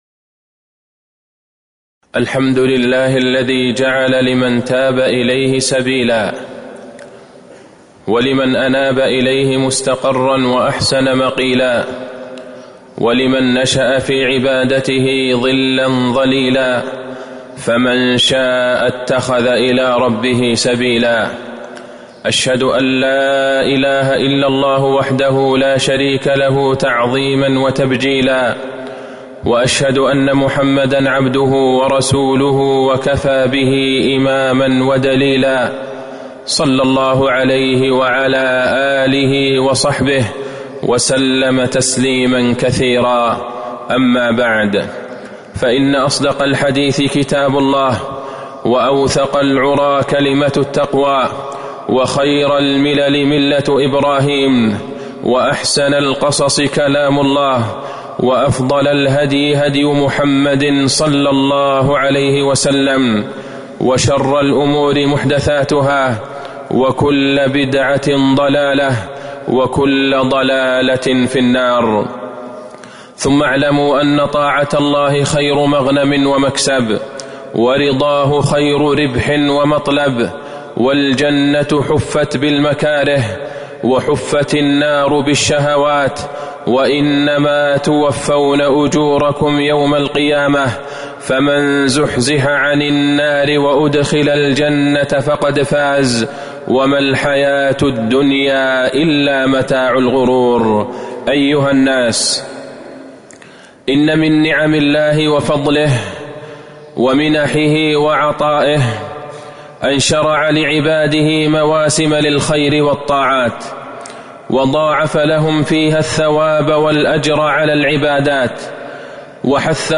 تاريخ النشر ١٥ شعبان ١٤٤٣ هـ المكان: المسجد النبوي الشيخ: فضيلة الشيخ د. عبدالله بن عبدالرحمن البعيجان فضيلة الشيخ د. عبدالله بن عبدالرحمن البعيجان استقبال شهر رمضان